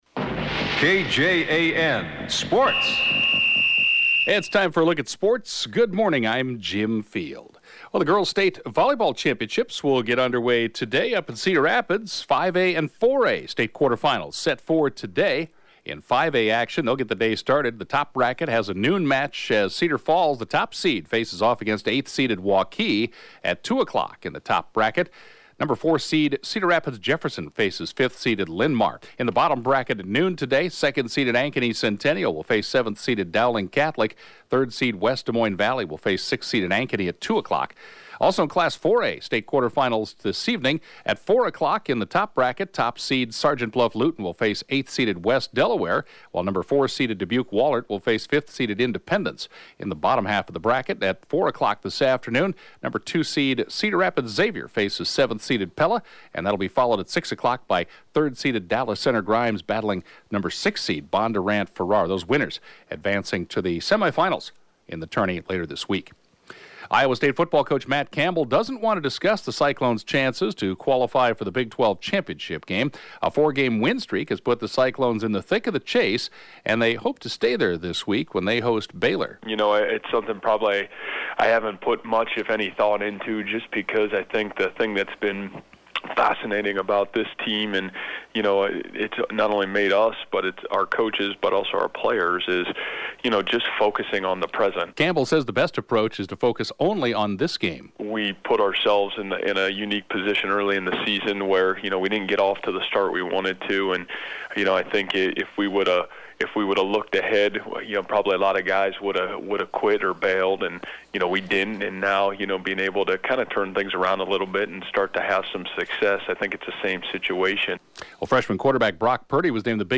(Podcast) KJAN Morning Sports report, 1/25/2017